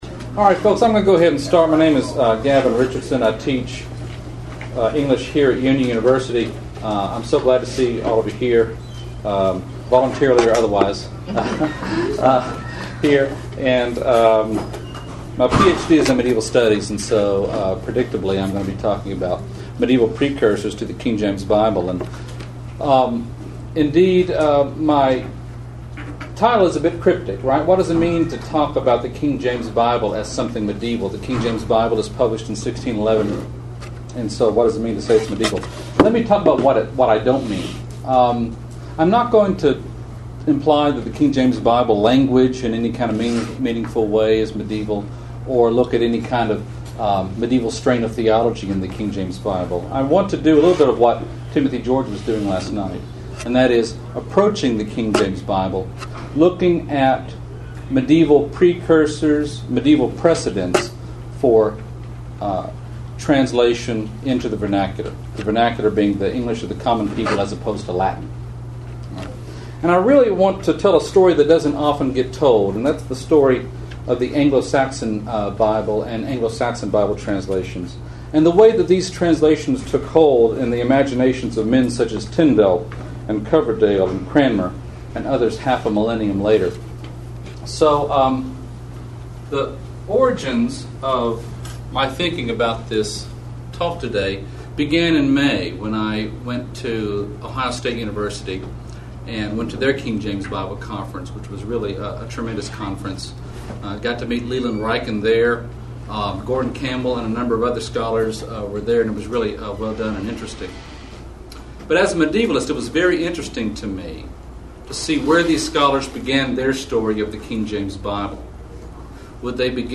KJV400 Festival
Union University Address